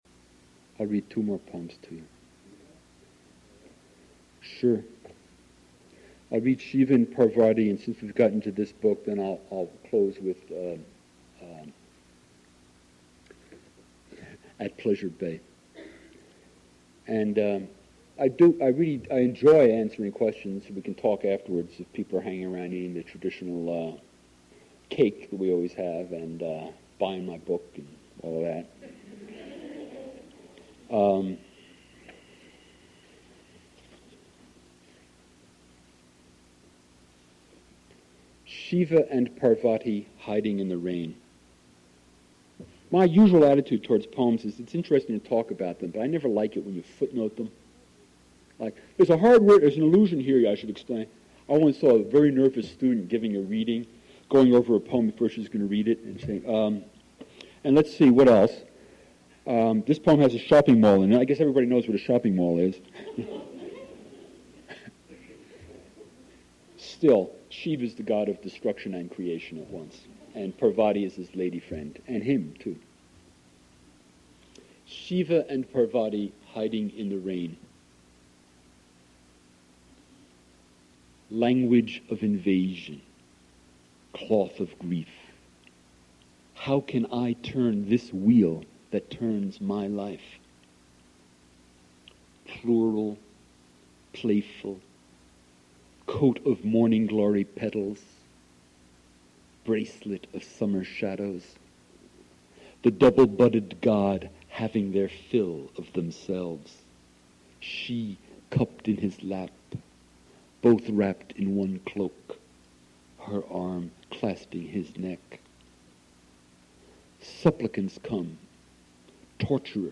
Spring Reading Series
Modern Languages Auditorium